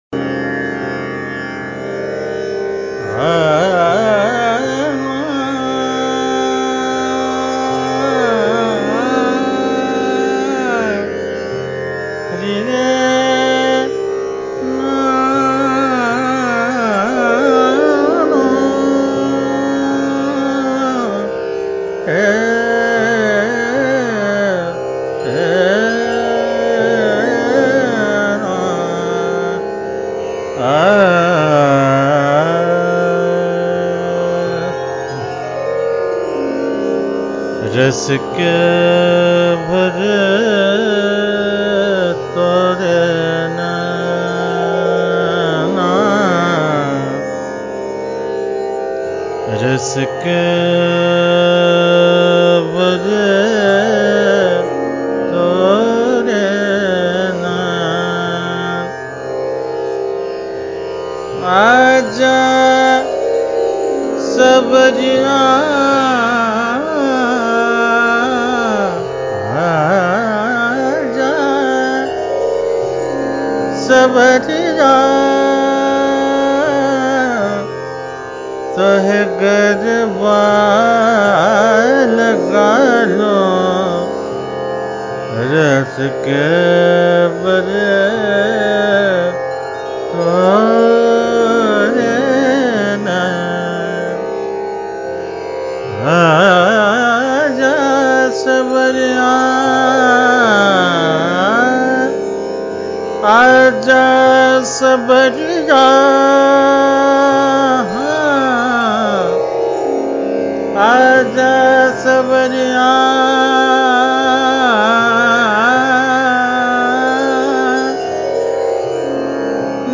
বেনারসের একটি ঐতিহ্যবাহী ঠুমরি।